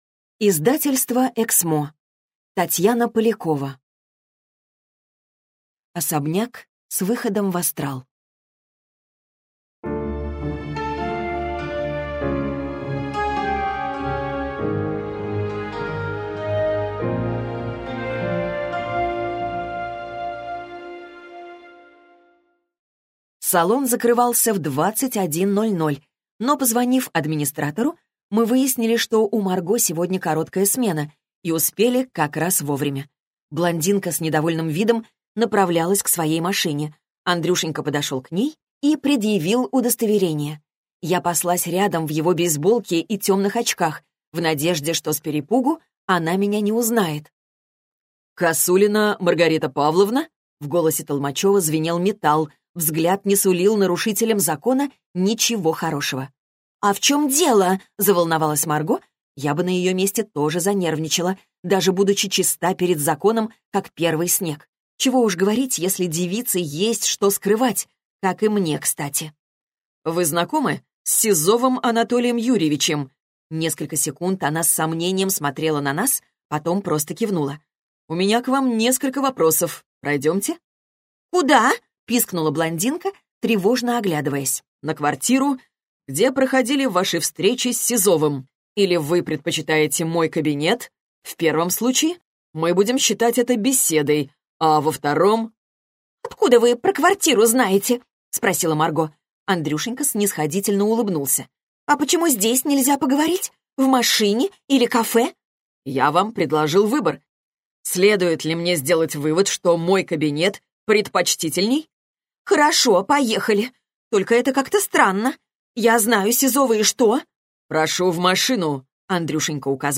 Аудиокнига Особняк с выходом в астрал - купить, скачать и слушать онлайн | КнигоПоиск